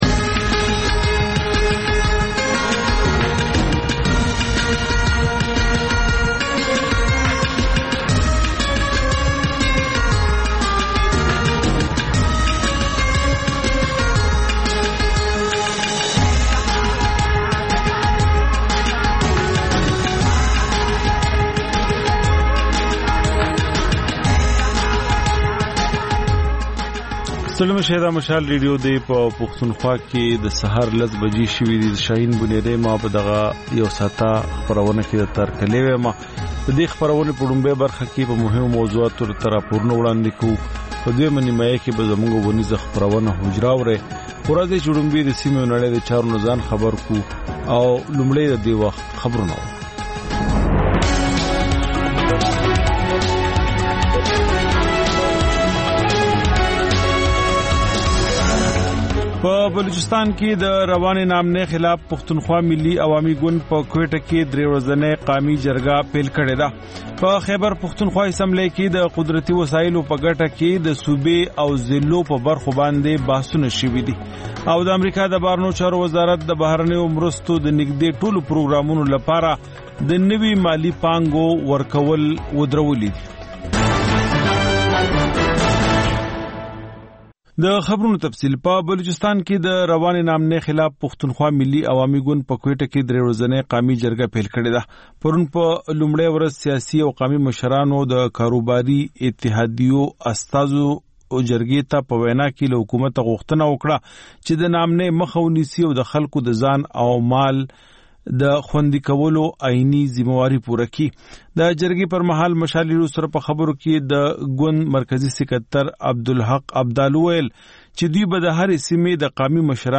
په دې خپرونه کې تر خبرونو وروسته بېلا بېل رپورټونه، شننې او تبصرې اورېدای شئ. د خپرونې په وروستۍ نیمايي کې اکثر یوه اوونیزه خپرونه خپرېږي.